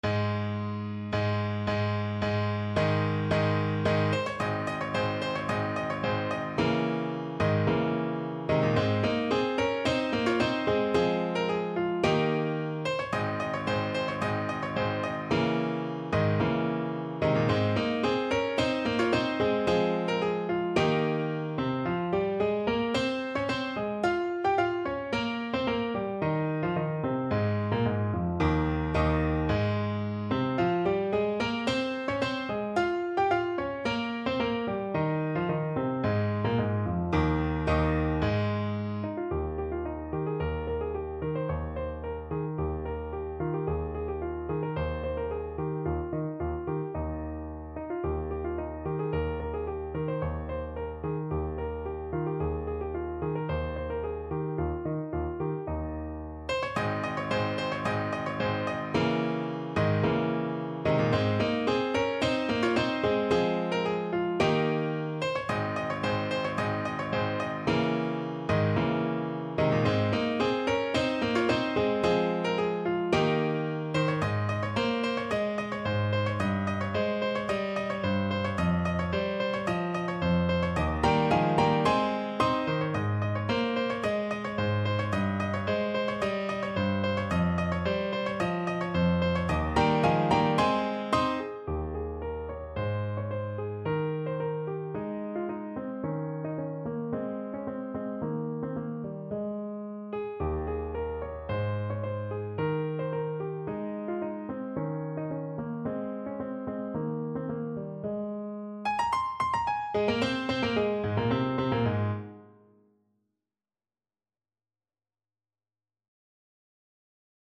= 110 Allegro di molto (View more music marked Allegro)